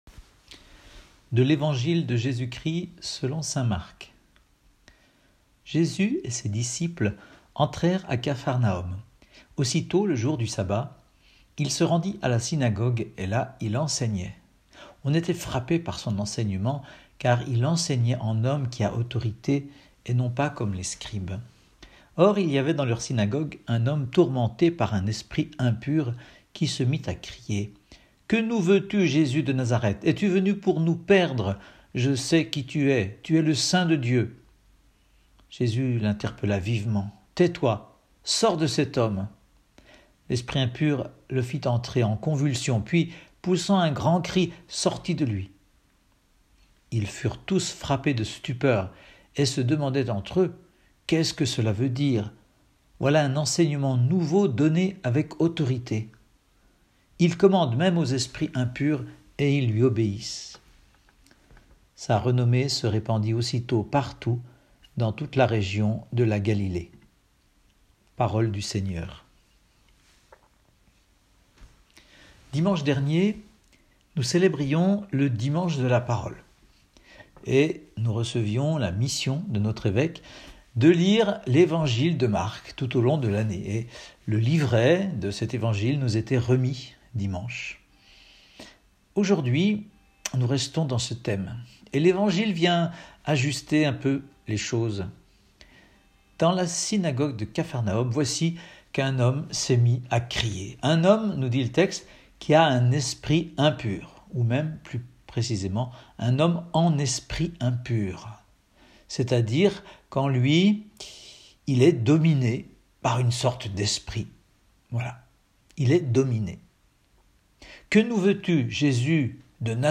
Evangile et homélie